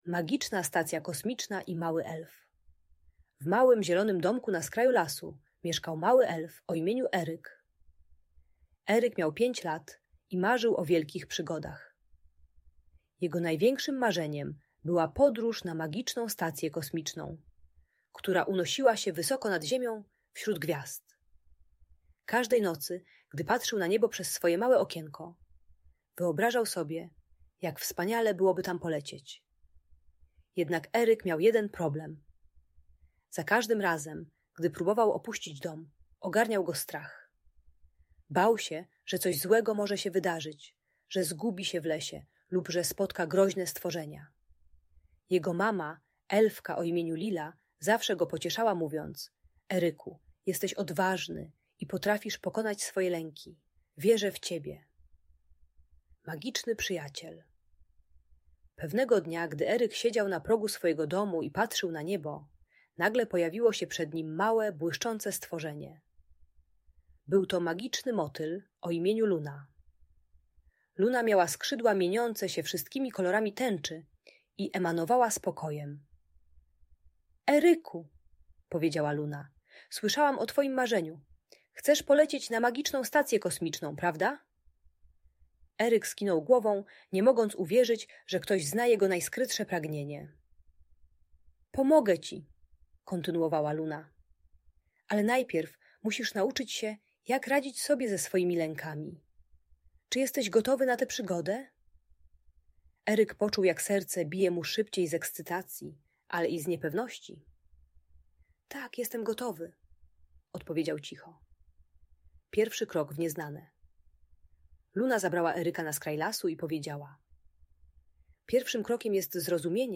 Magiczna Stacja Kosmiczna i Mały Elf - opowieść o odwadze - Audiobajka dla dzieci